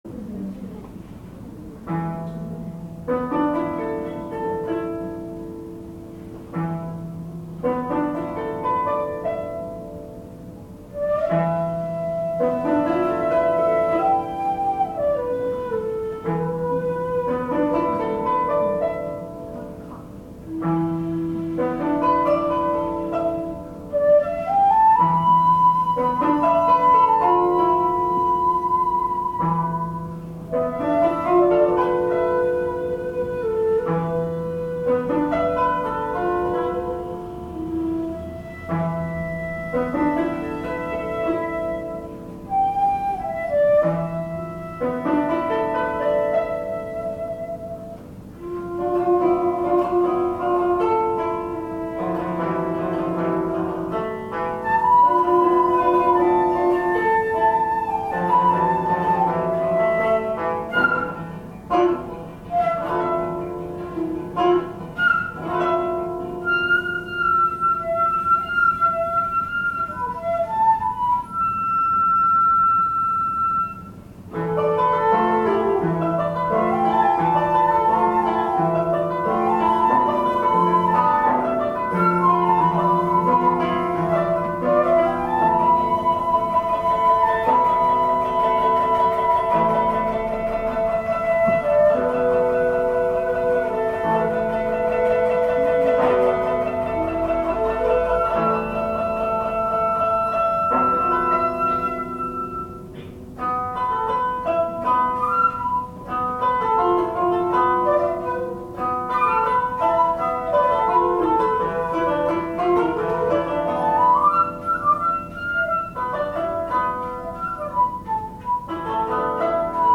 2年ぶりの小学校、琴･尺八鑑賞会
「春の海」は日本音階の曲ですので勿論平調子を元にします。
子供の飽きを心配して省略・後半カットの演奏でしたが、これなら全曲演奏してもよかったかなと思いました。